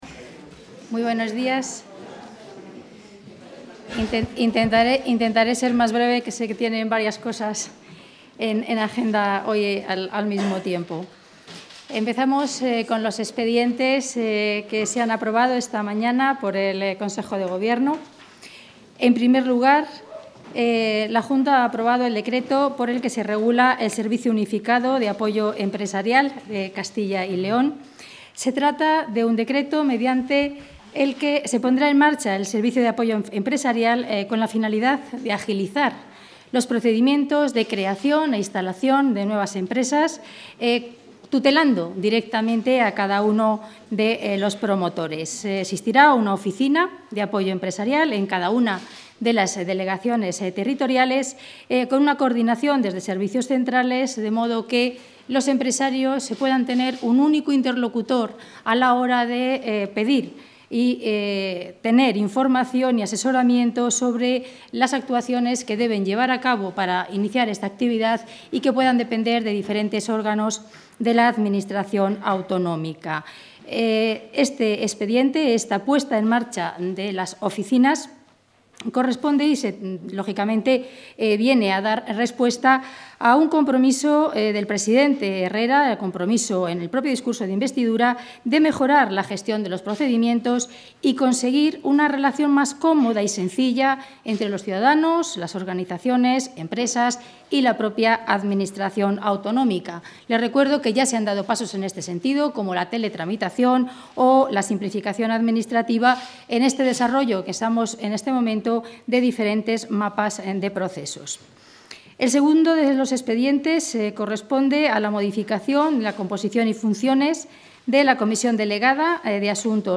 Audio rueda de prensa tras el Consejo de Gobierno.